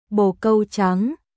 bồ câu trắng白い鳩ボー カウ チャン